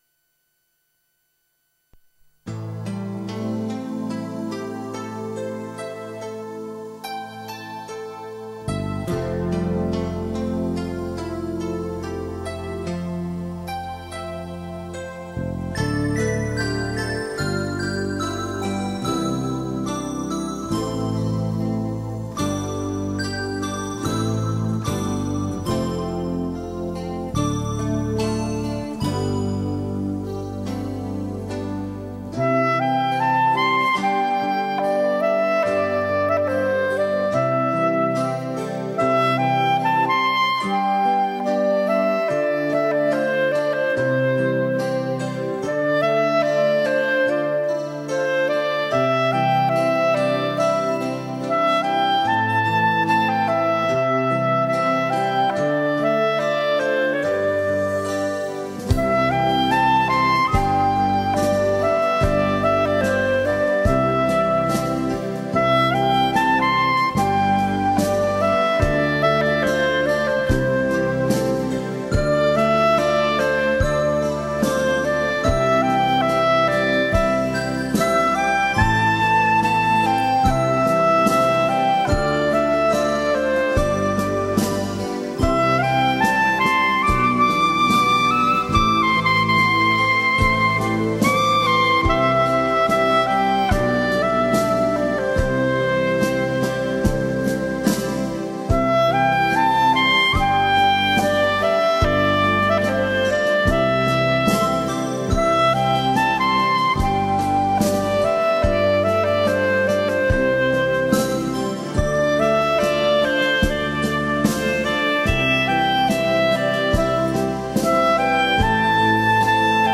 [10/9/2009]【双簧管】《飘雪》 激动社区，陪你一起慢慢变老！